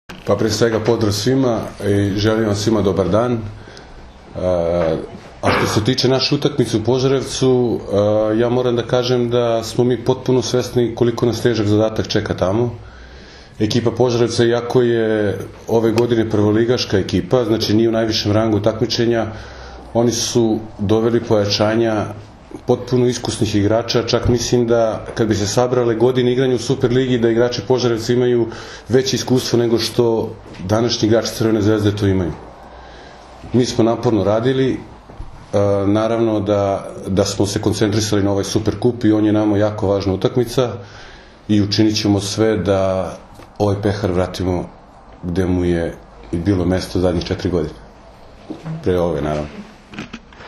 U prostorijama Odbojkaškog saveza Srbije danas je održana konferencija za novinare povodom utakmice VI Super Kupa Srbije 2016, koja će se odigrati sutra (četvrtak, 6. oktobar) od 18,00 časova u dvorani SC “Požarevac” u Požarevcu, između Crvene zvezde i Mladog radnika iz Požarevca, uz direktan prenos na RTS 2.
IZJAVA